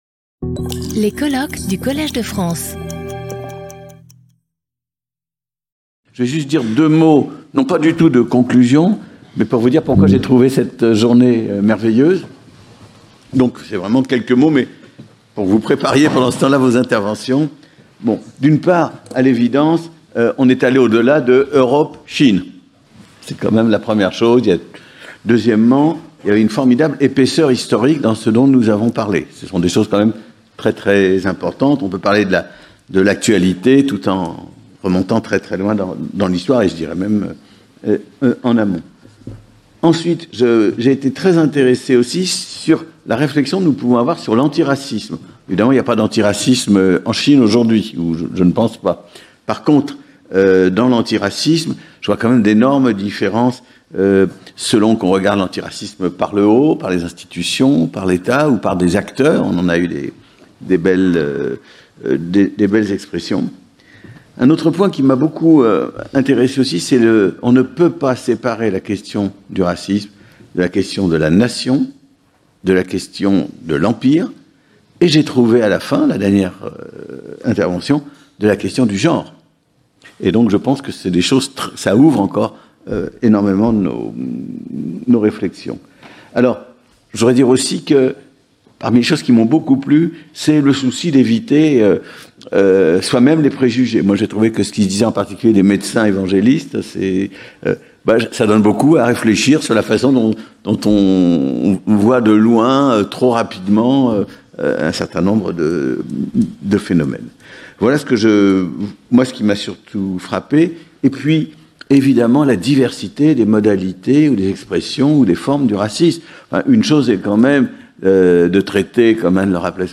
Conclusions and general discussion | Collège de France